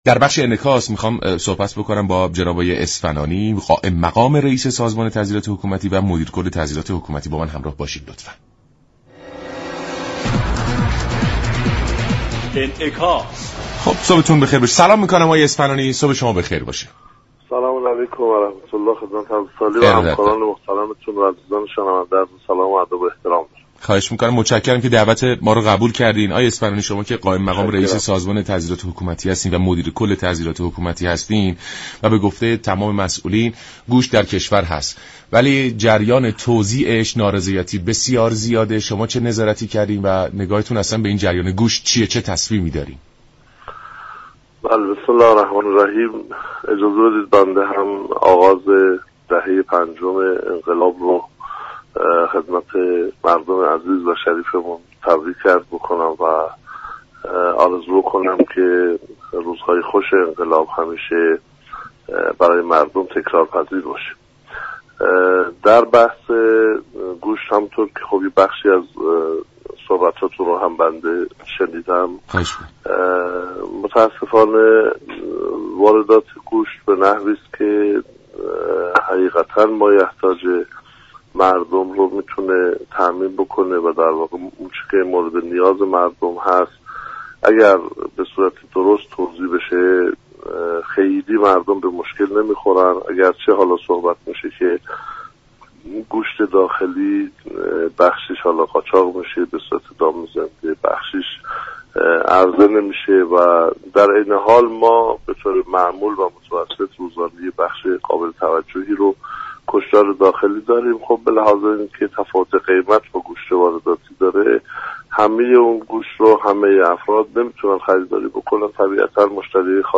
مدیر كل سازمان تعزیرات حكومتی استان تهران در گفت و گو با رادیو ایران گفت: سازمان تعزیرات حكومتی امكان نظارت بر همه مراكز را ندارد، از این رو سعی بر آن شده بحث نظارت را بر تعدادی محدود انجام دهیم.